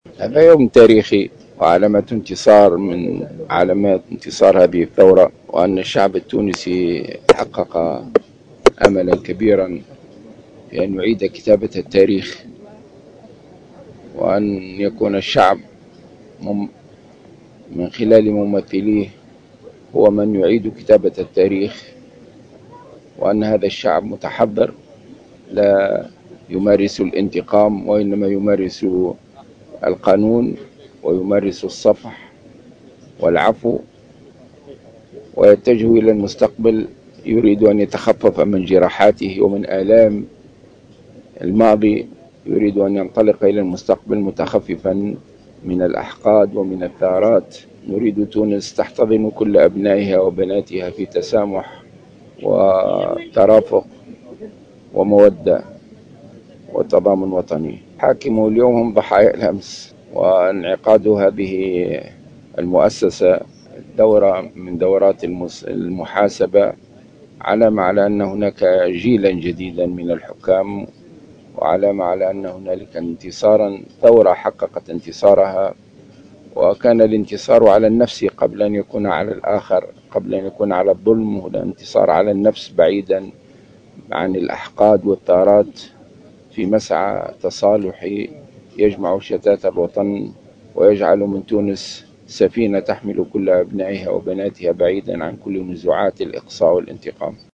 وقال الغنوشي في تصريح لمراسلة الجوهرة أف أم، اليوم الخميس، إن هذه الجلسات أثبتت تحضّر الشعب التونسي الذي نأى بنفسه عن ممارسة الانتقام وخيّر ممارسة القانون والصفح والعفو والتوجه نحو المستقبل بعيدا عن الأحقاد ومنطق الثأر.